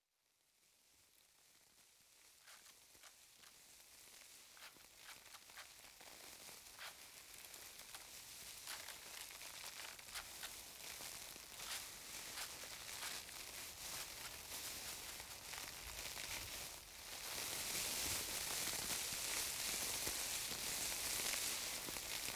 06_公寓楼道_花枯萎.wav